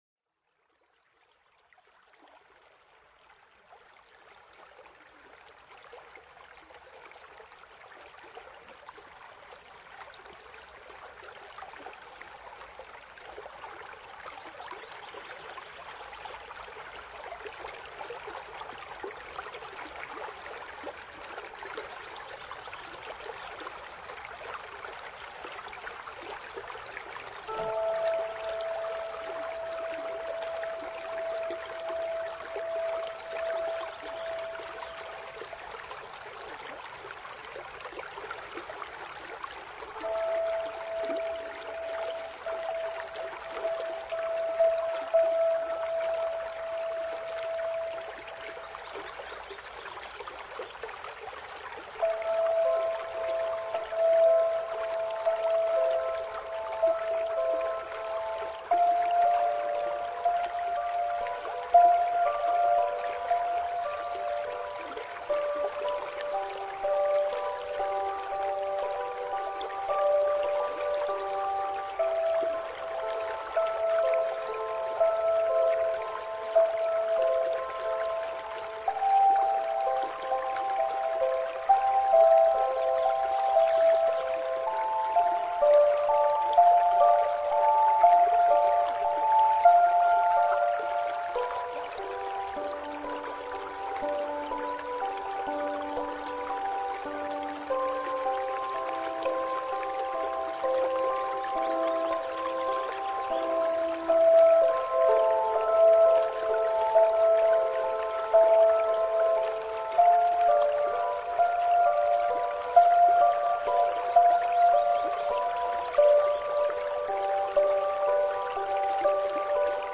nature sounds and atmospheric sounds, perfect for relaxation
ambient
new age